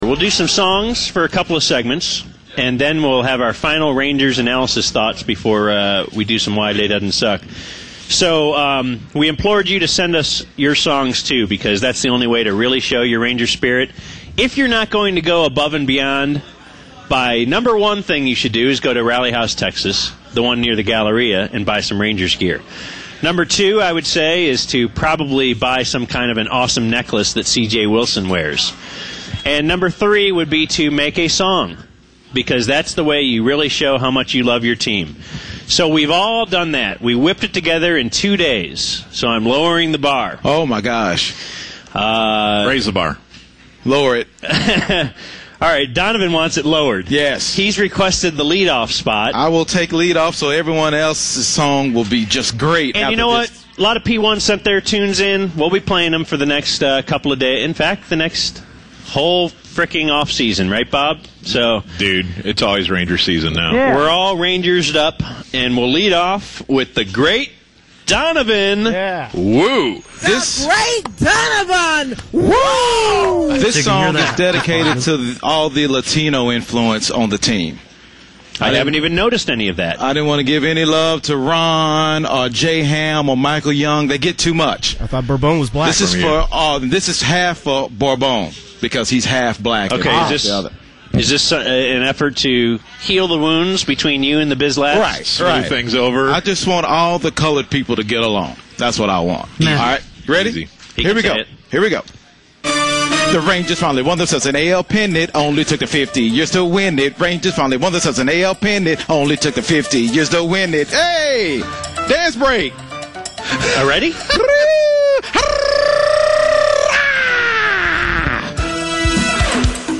BaD Radio - World Series Song Montage - The UnTicket